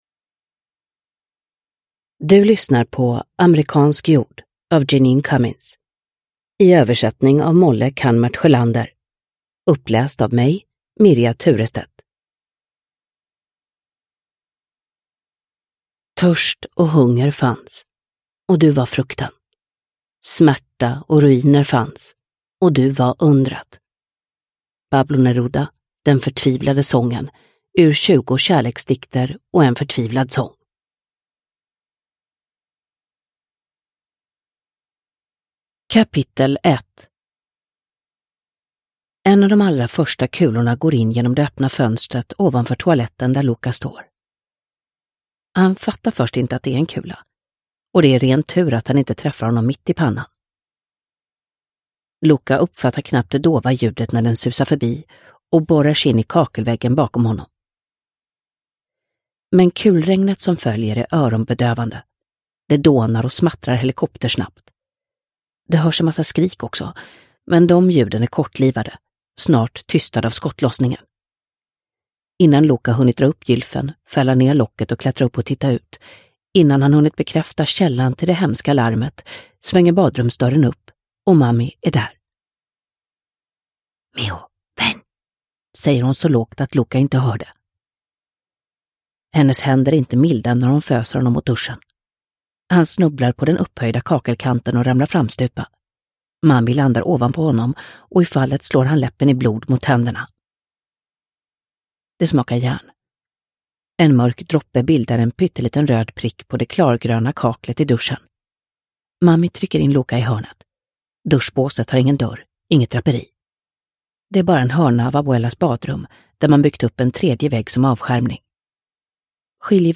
Amerikansk jord – Ljudbok – Laddas ner
Uppläsare: Mirja Turestedt